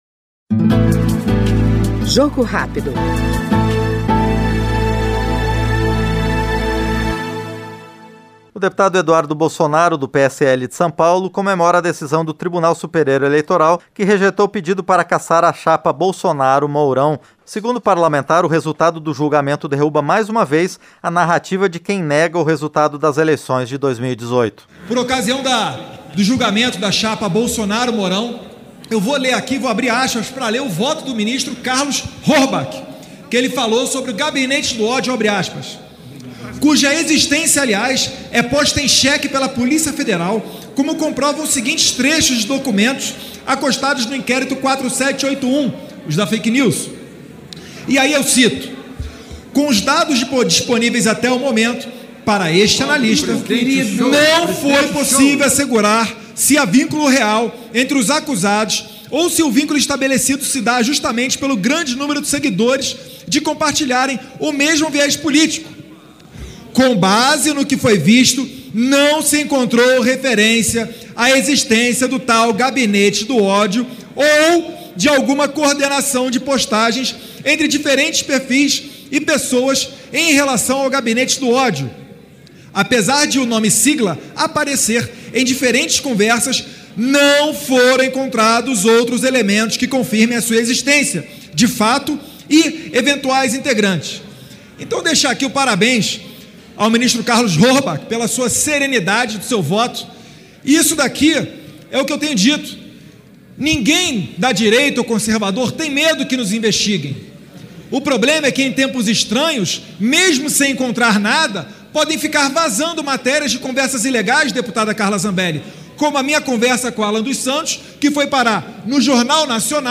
Jogo Rápido é o programa de entrevistas em que o parlamentar expõe seus projetos, sua atuação parlamentar e sua opinião sobre os temas em discussão na Câmara dos Deputados.